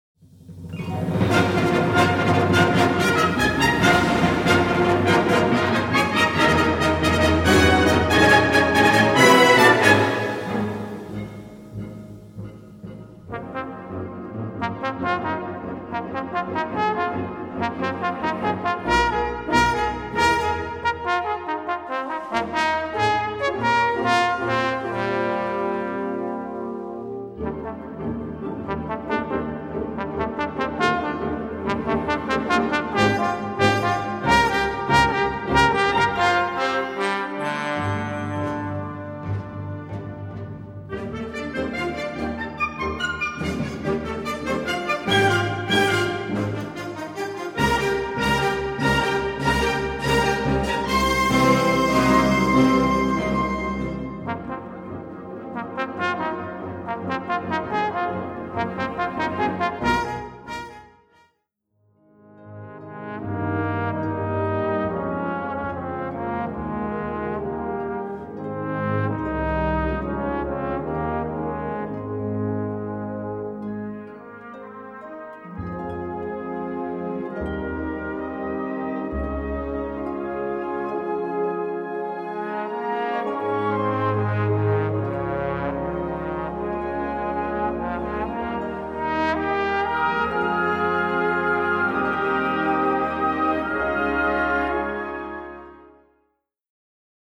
Voicing: Trombone w/ Band